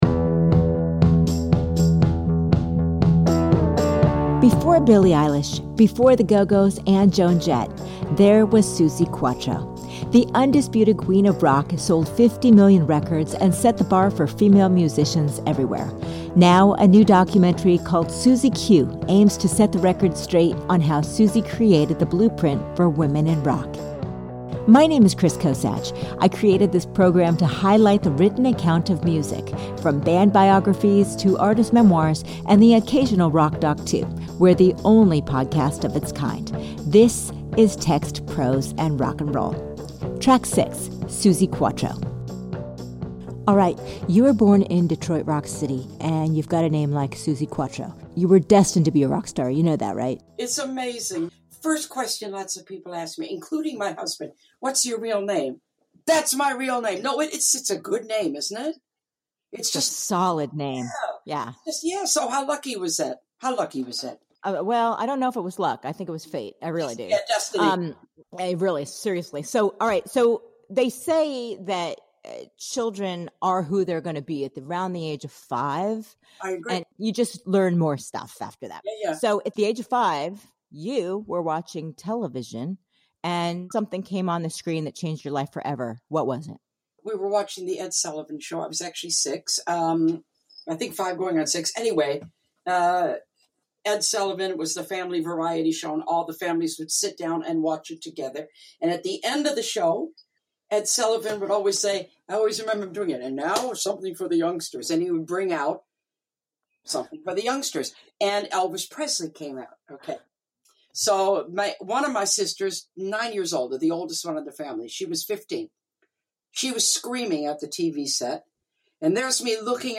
You'll hear first accounts from those who lived the lifestyle; a Book Club that rocks - literally.